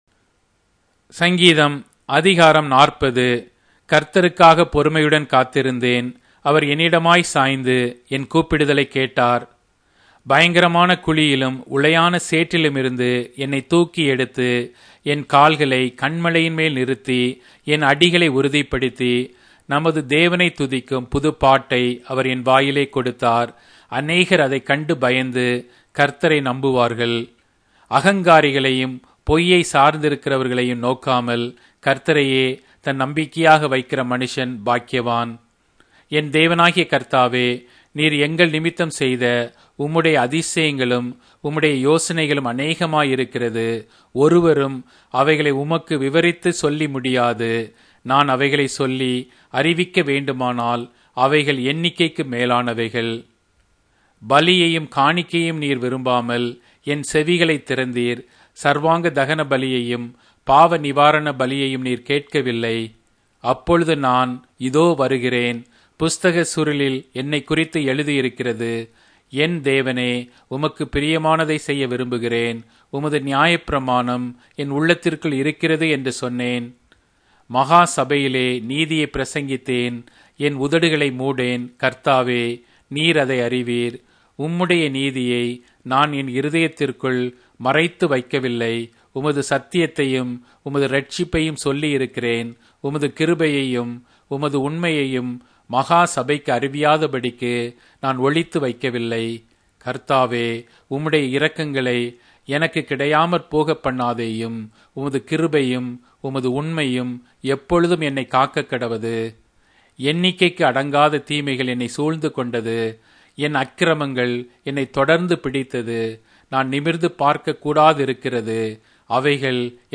Tamil Audio Bible - Psalms 55 in Mrv bible version